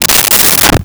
Desk Drawer Opened 02
Desk Drawer Opened 02.wav